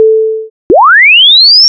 1. A tone of decreasing amplitude during 1/2 seconds,
2. 1/5 seconds of silence,
3. A tone of increasing frequency during 1 second.